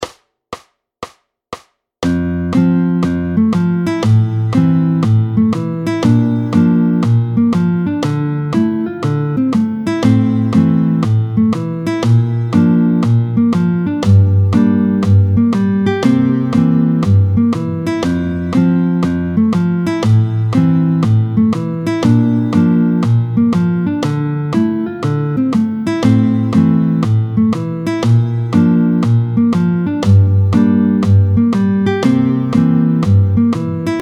13-07 Exercice en Fa, tempo 60